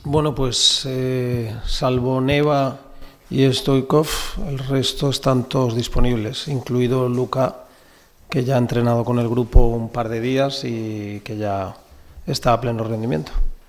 El entrenador del Granada CF, Fran Escribá, ha comparecido ante los medios en sala de prensa con motivo de la previa del derbi que se disputará este sábado a las 18:30 horas en La Rosaleda.